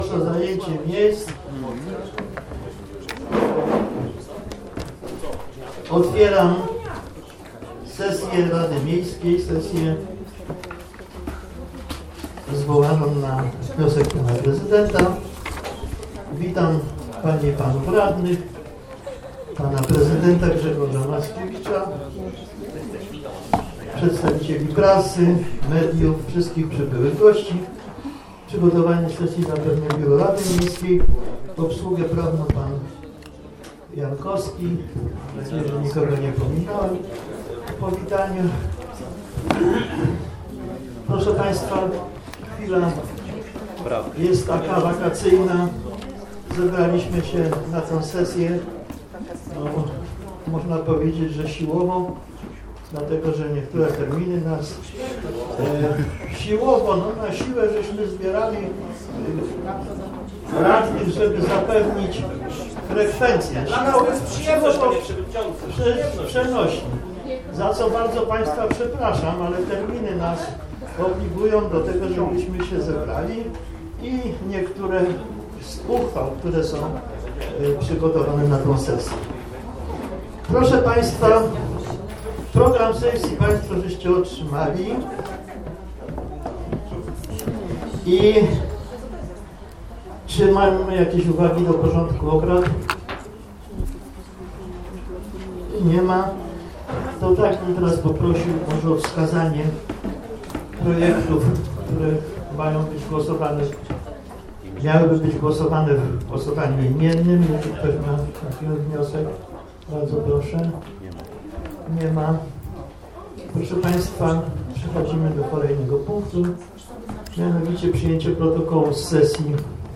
XLV sesja Rady Miejskiej w Pabianicach - 17 sierpnia 2017 r. - 2017 rok - Biuletyn Informacji Publicznej Urzędu Miejskiego w Pabianicach